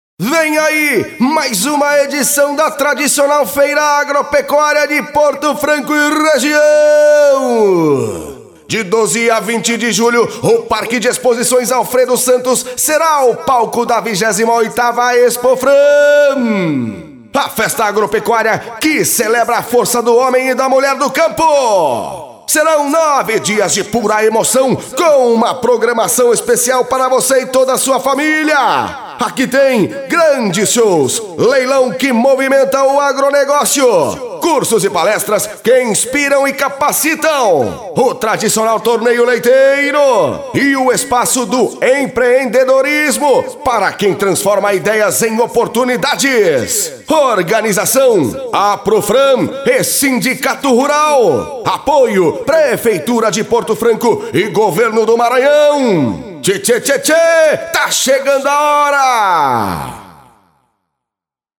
RODEIO OFF: